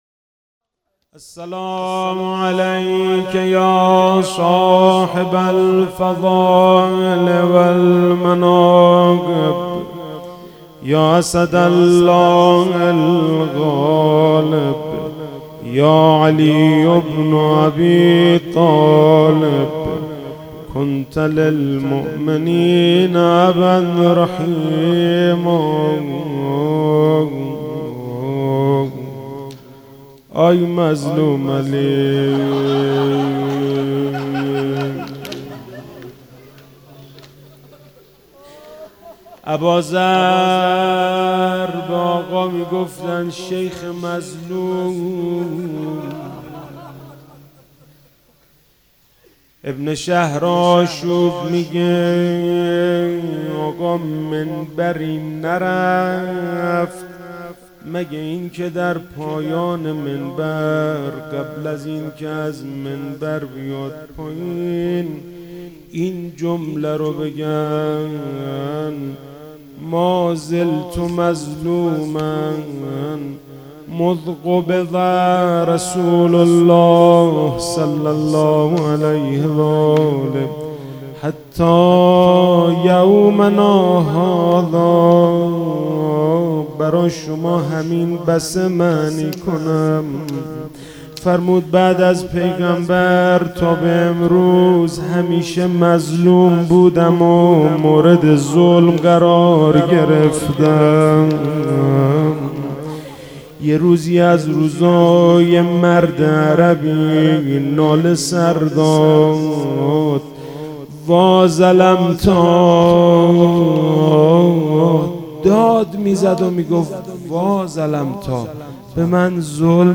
روضه حضرت علی علیه السلام